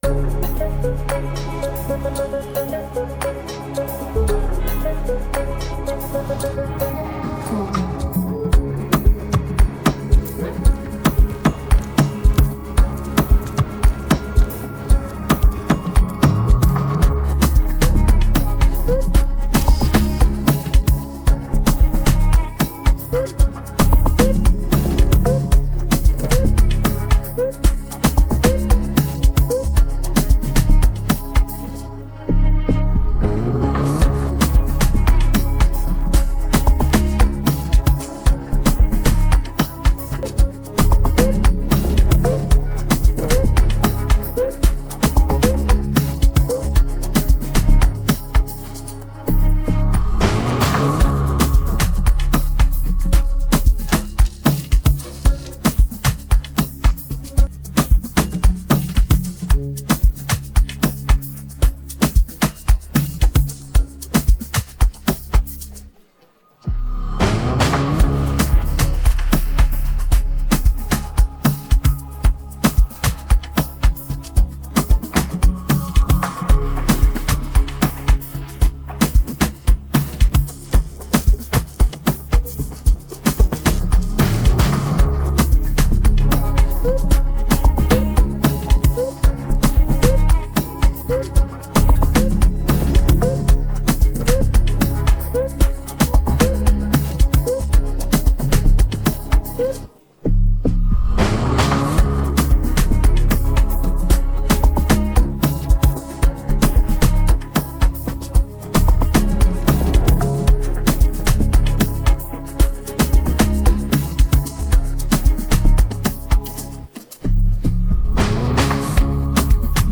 AfrobeatsAmapaino